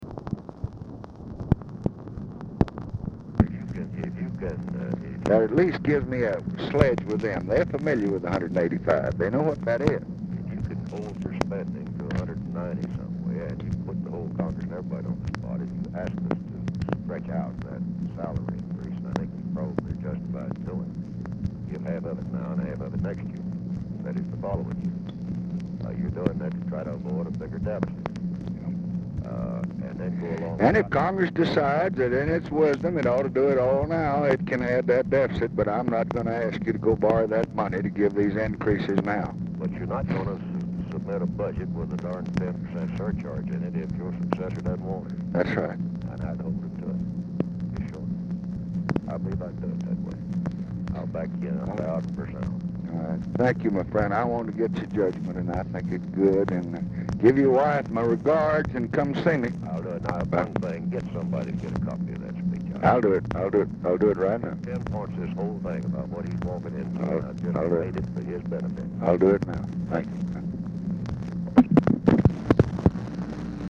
Telephone conversation # 13744, sound recording, LBJ and WILBUR MILLS, 11/16/1968, 12:01PM | Discover LBJ